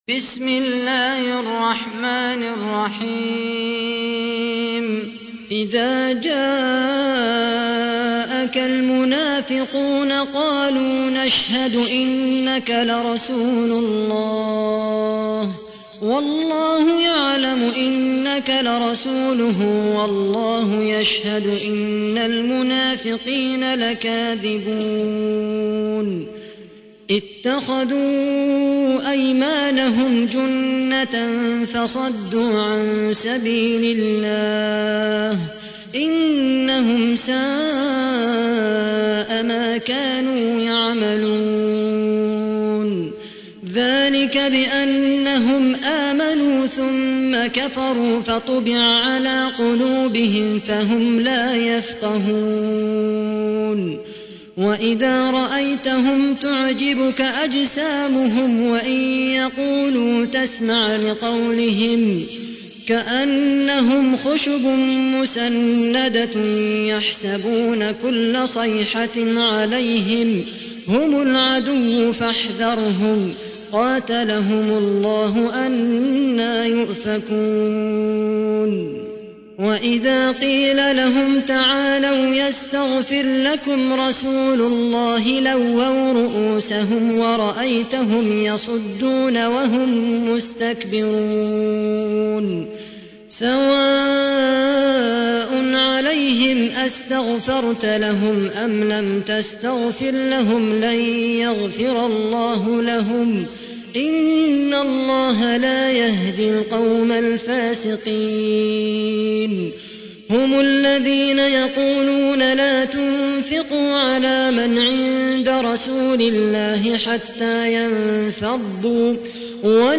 تحميل : 63. سورة المنافقون / القارئ خالد القحطاني / القرآن الكريم / موقع يا حسين